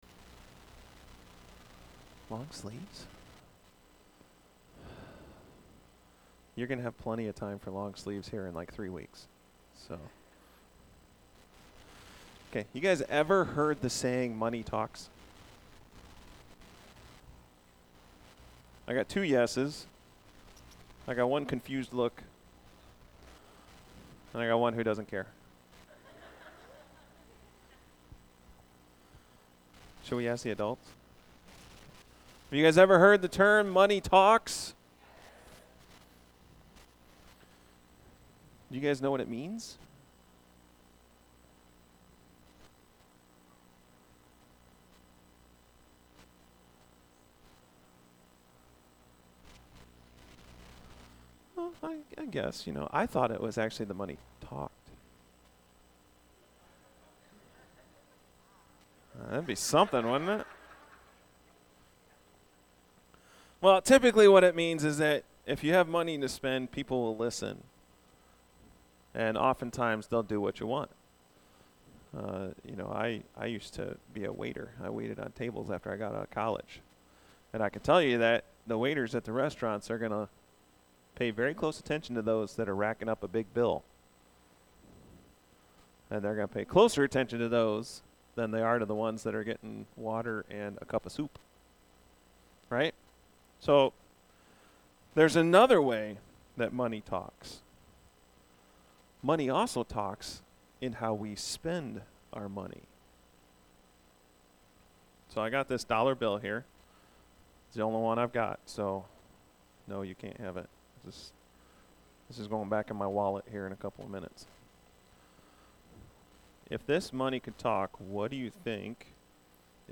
A message from the series "Acts."